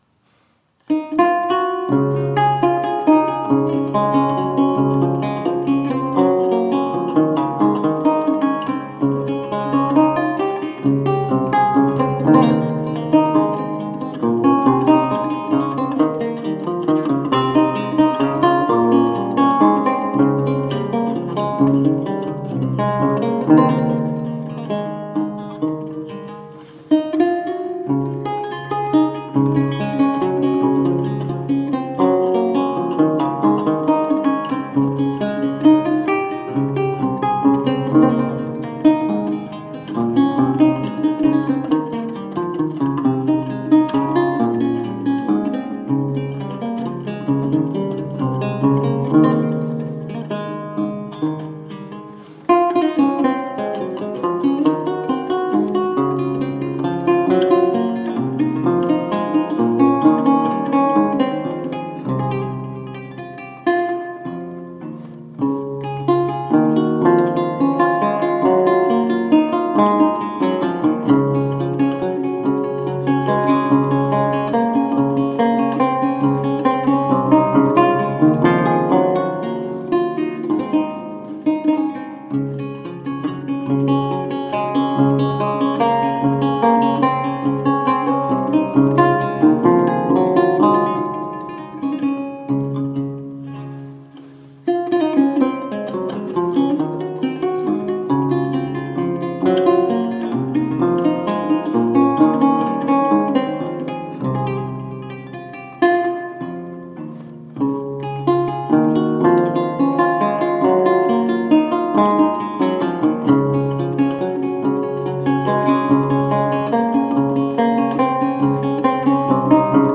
Vihuela, renaissance and baroque lute.